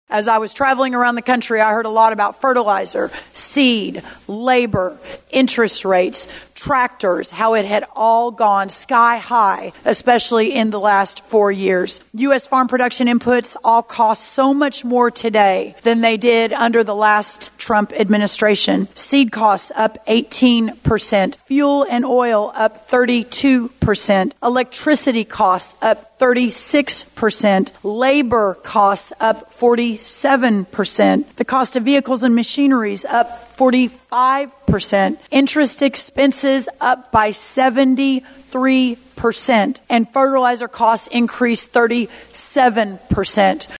During the recent Agri-Pulse Ag Outlook Forum, U.S. Agriculture Secretary Brooke Rollins talked about how much costs have risen since the first Trump administration.